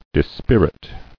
[dis·pir·it]